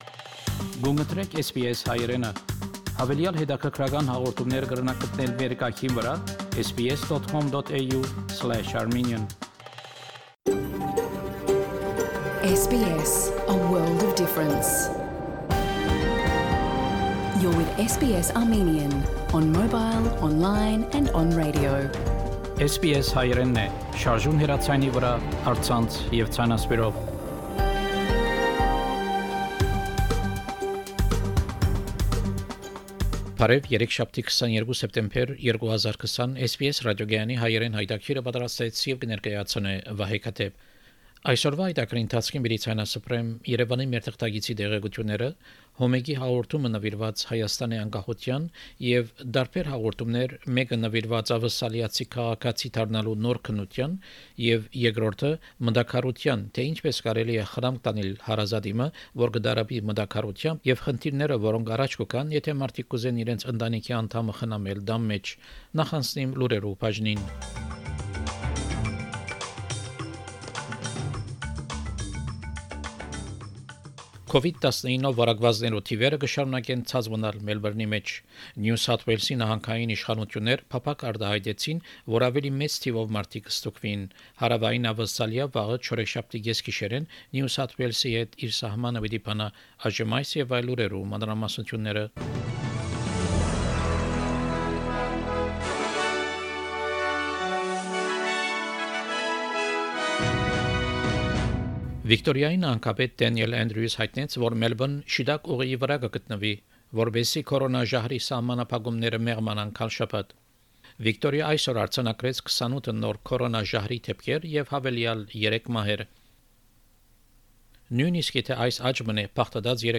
SBS Armenian news bulletin – 22 September 2020
SBS Armenian news bulletin from September 22, 2020 program.